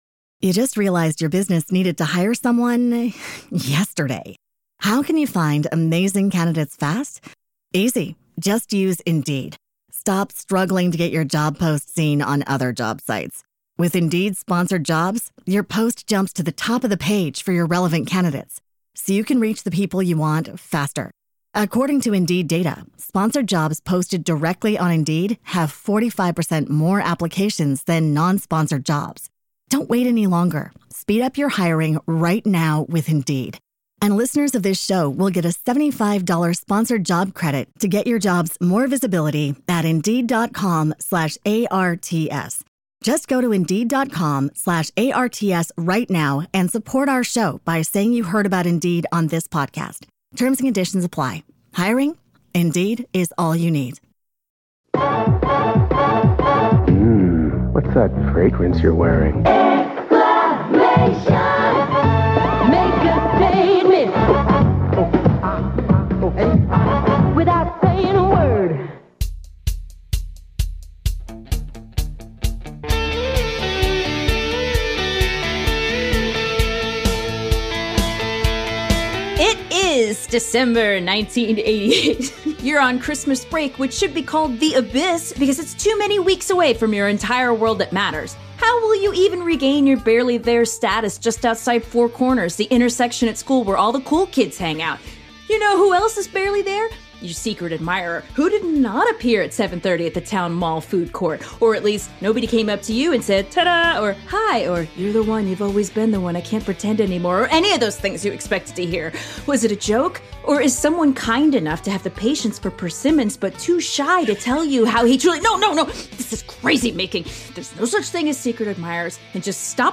Your co-hosts share their results from the December 1988 quiz -- How Honest Are You? -- and then play some of the most recent voicemails left on our hotline (720-SASSY-GO -- just program it into your phone ...